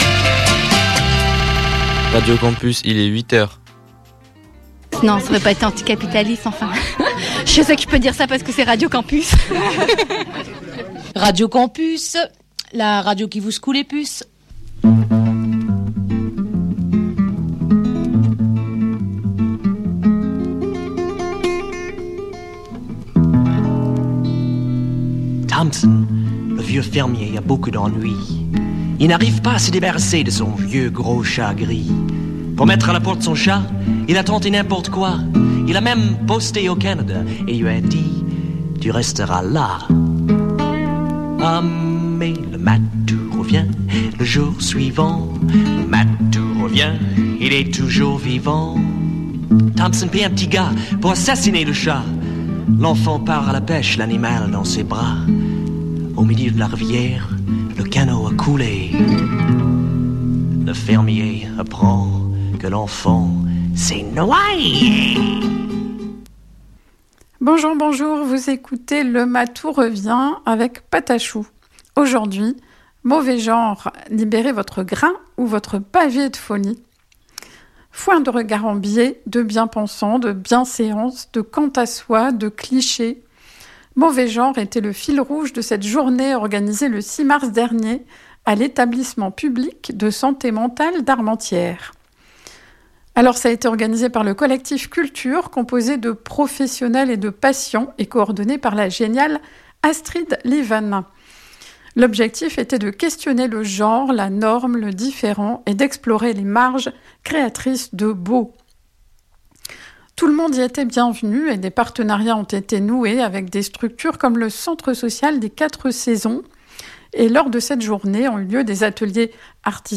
« Mauvais genre » était le fil rouge de cette journée organisée à l’Etablissement public de santé mentale d’Armentières.
Une bonne occasion pour changer de regard sur les uns et les autres... Avec les chansons de la chorale Chauffe Marcelle qui s’est époumonée, avec le concours de participants aux ateliers chant.